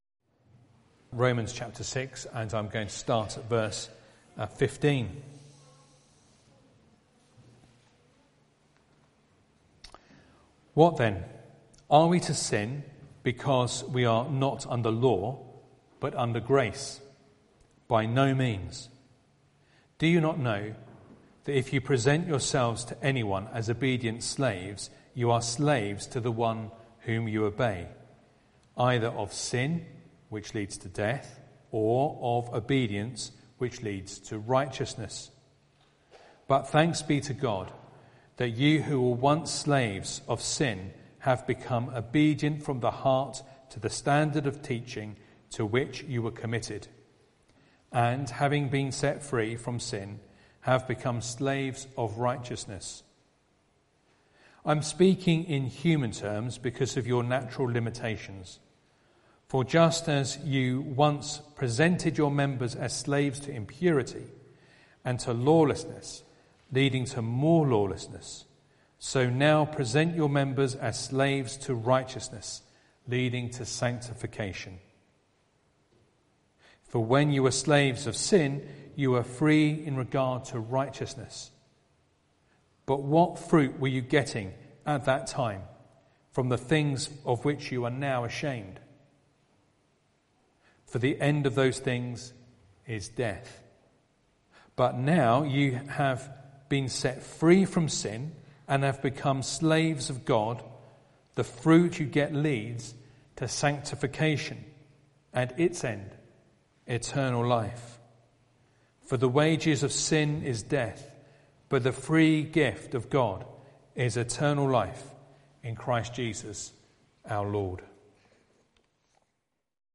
Sunday Evening Reading and Sermon Audio